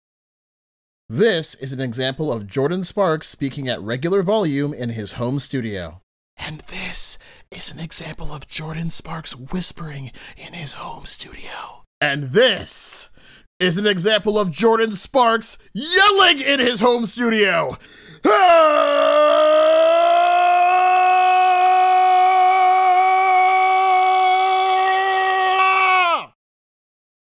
Home Studio Sound Range
Rode NT1-A Microphone, Scarlett 2i2, Mic Stand, Pop Filter, Isolation Shield, Logic Pro